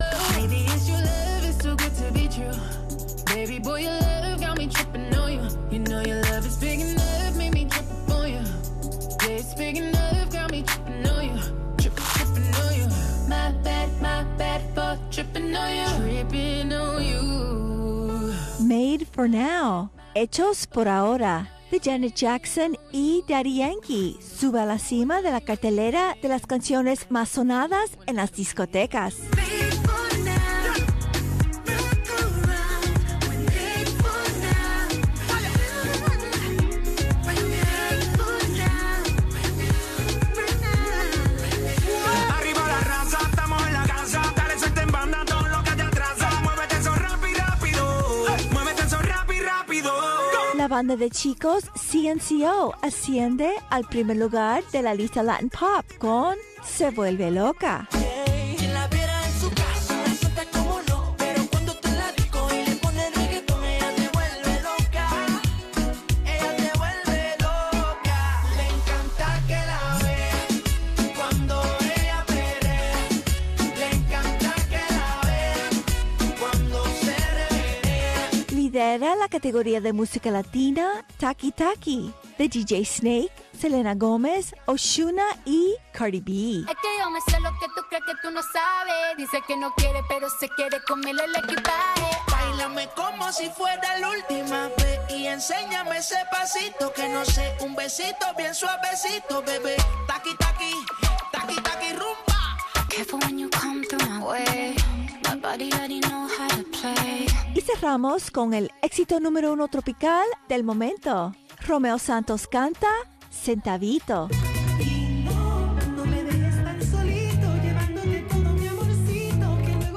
Programa conducido por el Doctor Oscar Elías Biscet